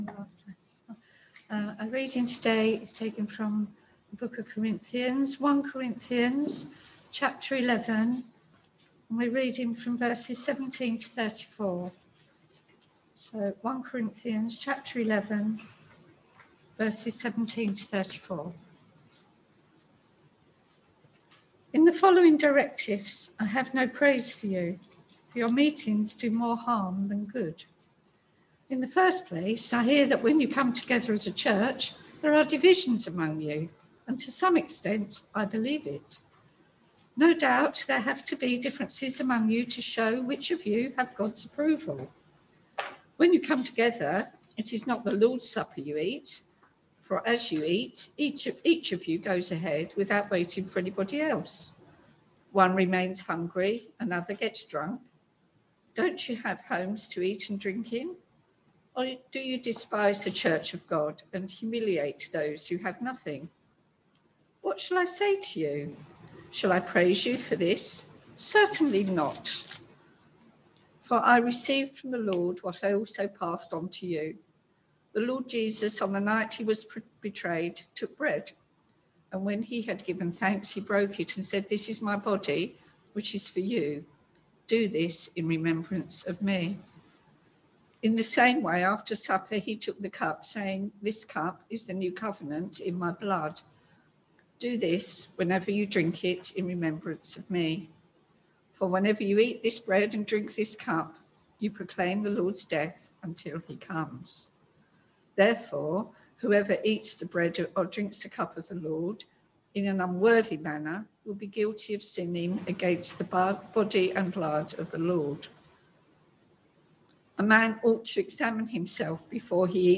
Passage: 1 Corinthians 11v17-34 Service Type: Sunday Morning Service Topics: Church Life Hebrews 1v1-4 »